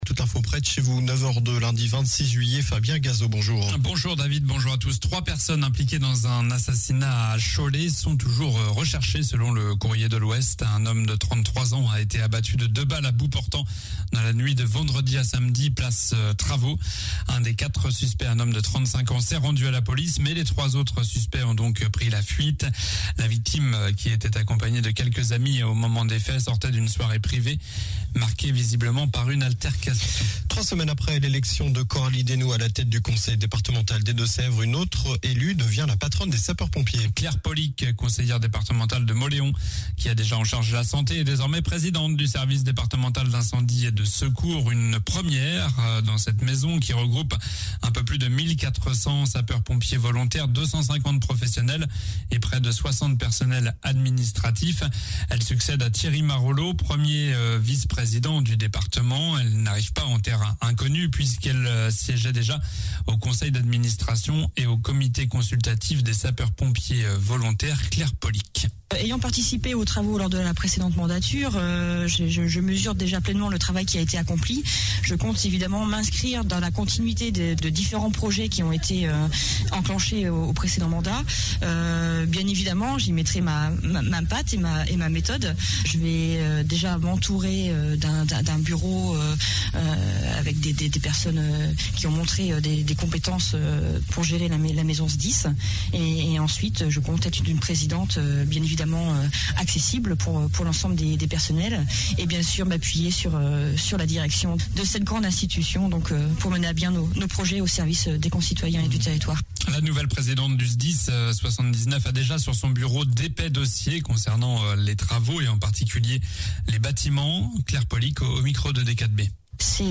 Journal du lundi 26 juillet (matin)